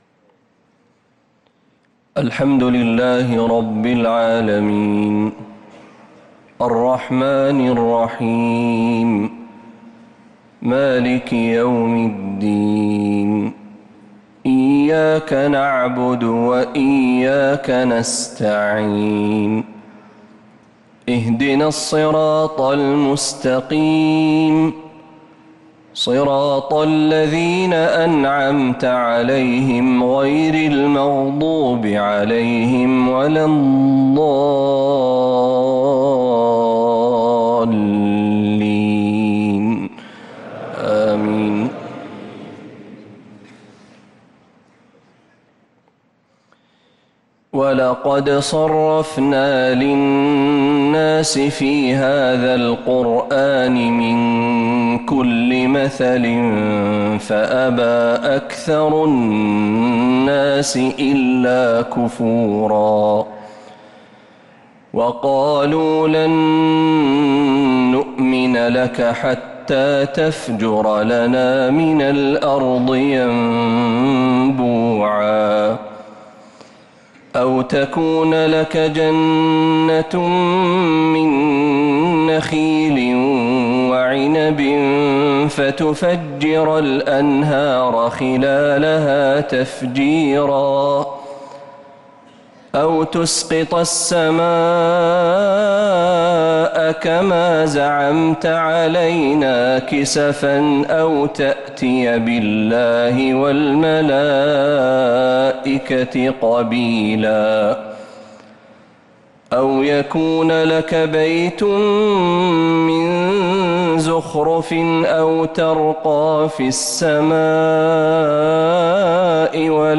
مغرب الإثنين 9-3-1447هـ | من سورة الإسراء 89-96 | Maghrib prayer from Surah Al-Israa 1-9-2025 > 1447 🕌 > الفروض - تلاوات الحرمين